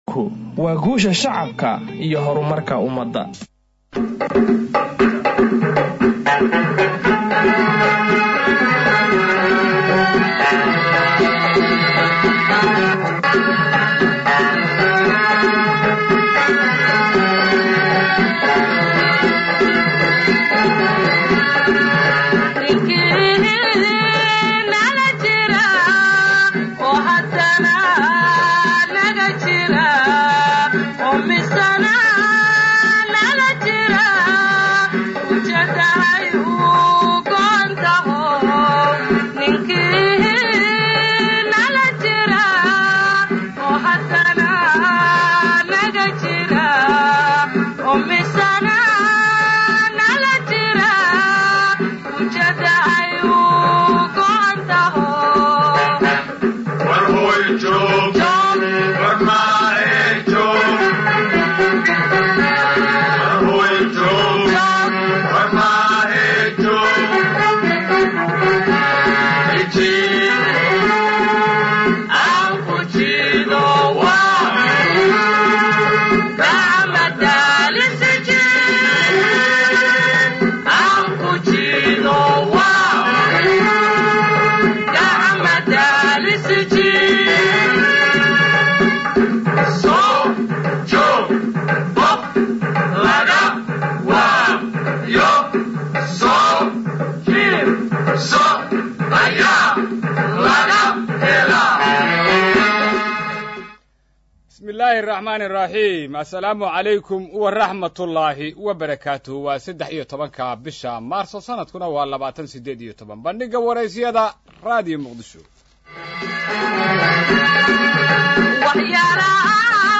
Dhageyso Barnaamjika Bandhigga Wareysiyada ee Radio Muqdisho